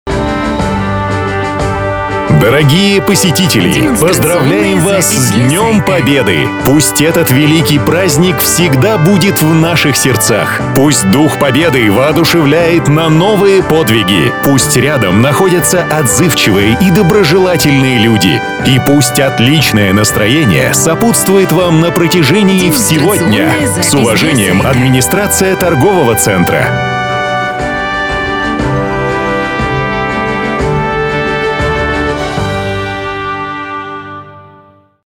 034_Поздравление с 9 мая_мужской.mp3